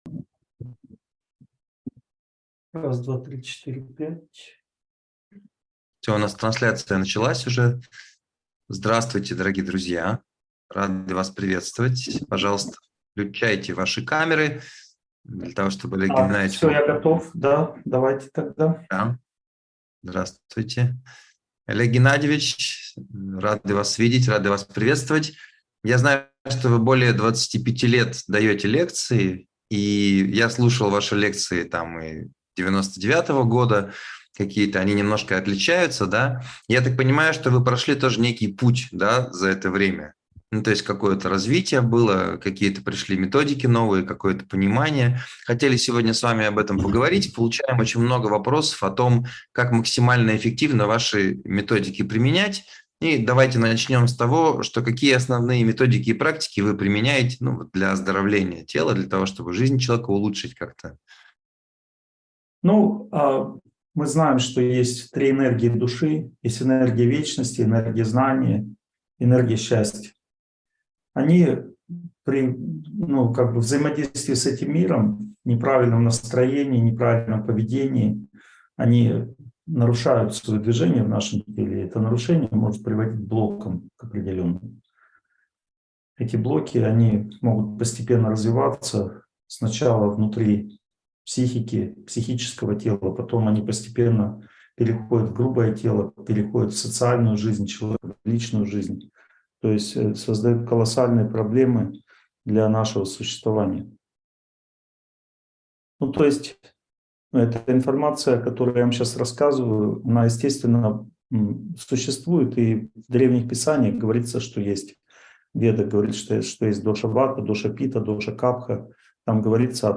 Секреты моих авторских методик (вебинар, 2023)